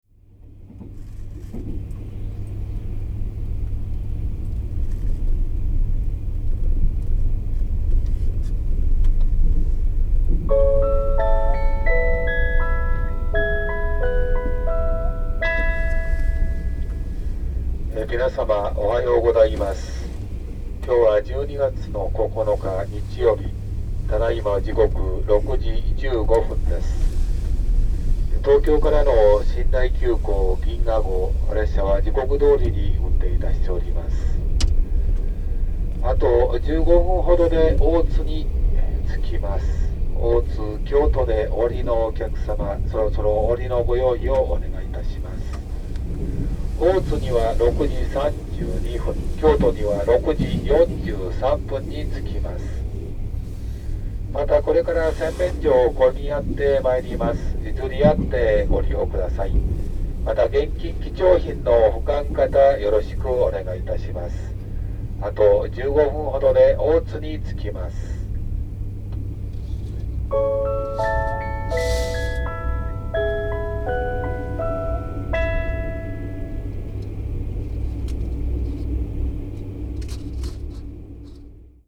おはよう放送（101レ「銀河」　オロネ24 103車内）